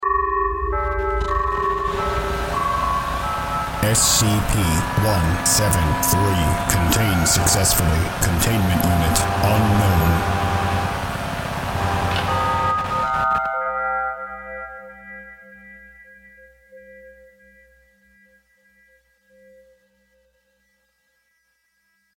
Announcements